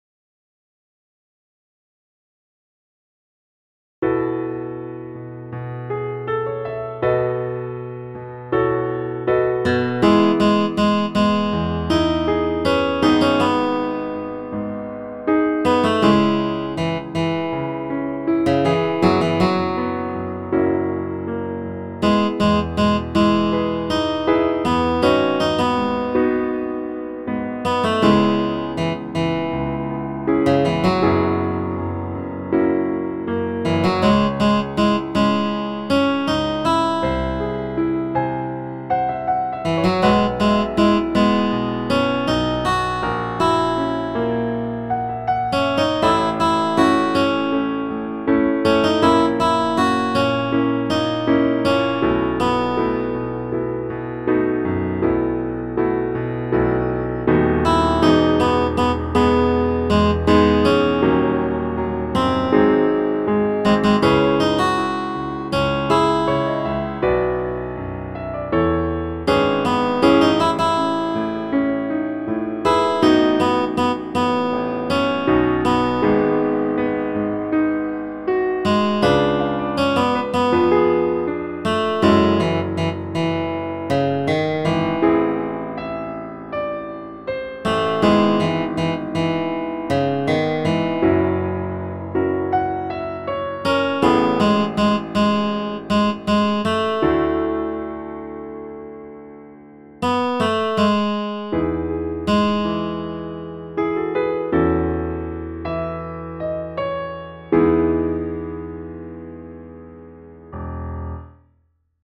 WHEN SOMEONE COMES INTO YOUR LIFE (accomp-melody)